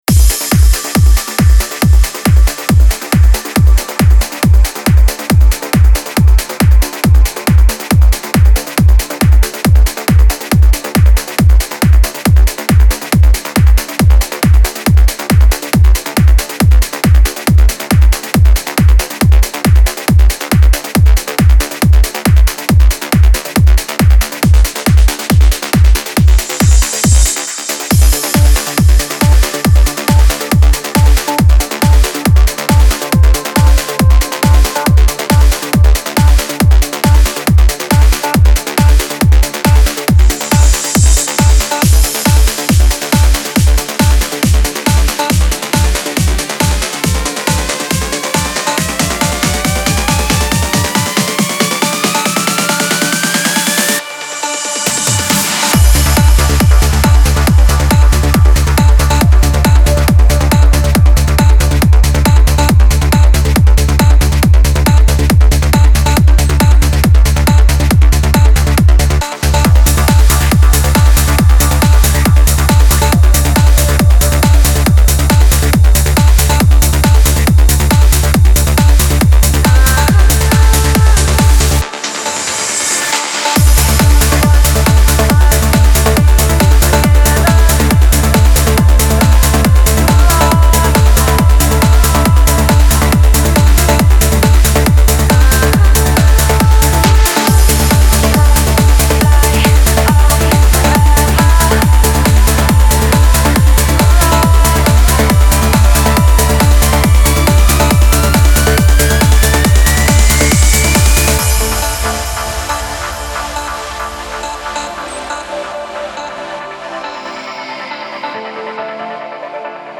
Genre: House, Trance, Electronic, Dance.